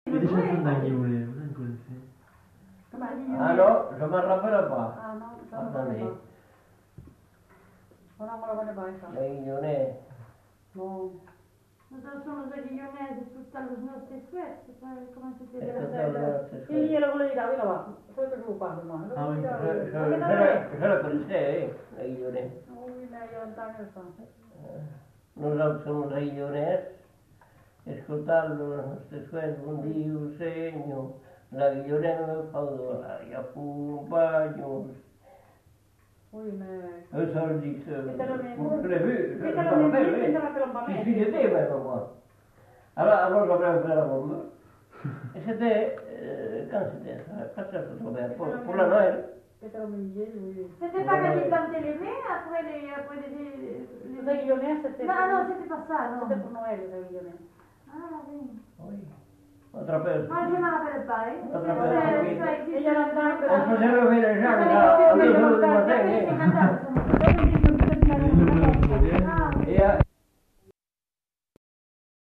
Lieu : Sainte-Maure-de-Peyriac
Genre : témoignage thématique